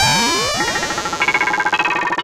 Glitch FX 21.wav